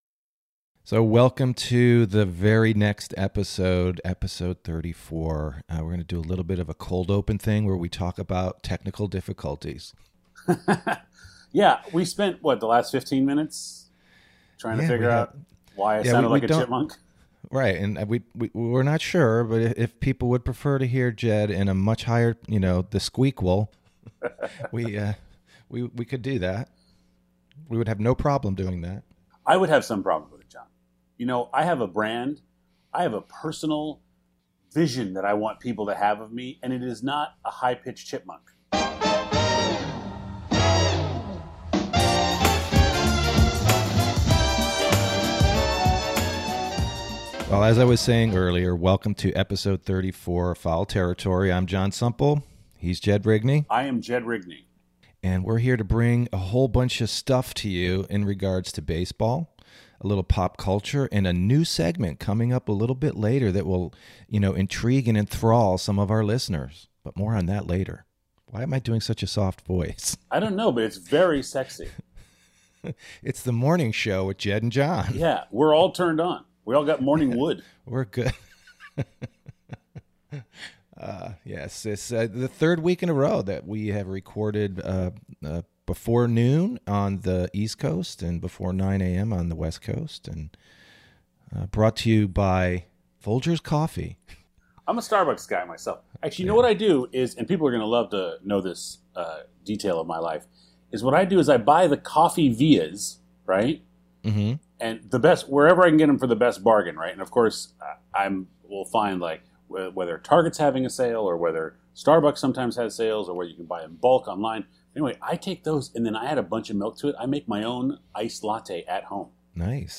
Tune in for the new segment Seventh Inning Stretch, where we talk about the top five best baseball movies of all time! Tech woes show up for Episode 34 when a faulty microphone causes havoc at the outset, but once ...